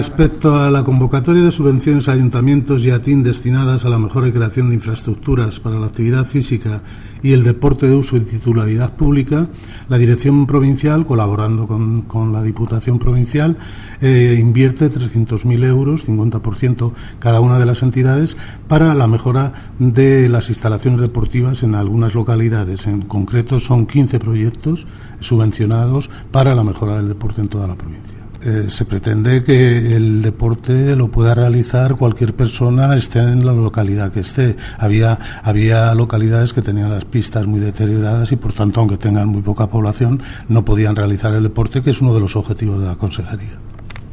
El director provincial de Educación, Cultura y Deportes, Faustino Lozano, habla de la subvenciones para la remodelación de pistas deportivas en las zonas rurales de la provincia de Guadalajara.